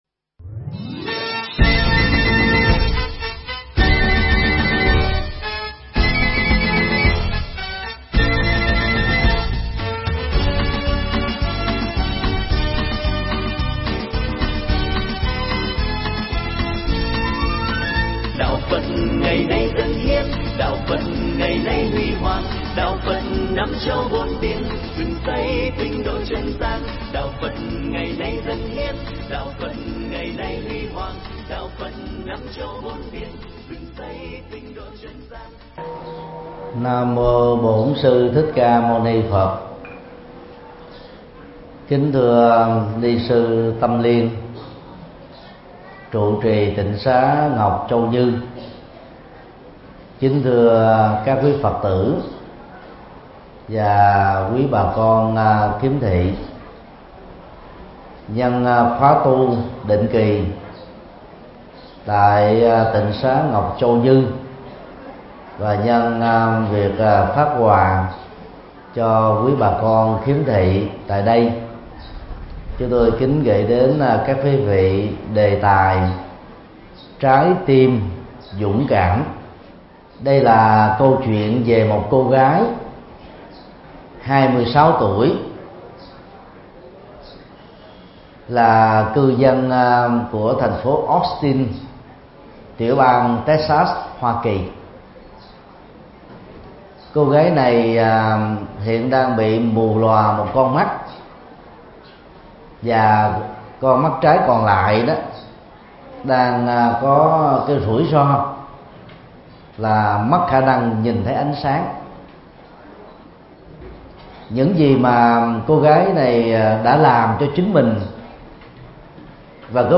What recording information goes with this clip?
Giảng tại tịnh xá Ngọc Châu Như, Sóc Trăng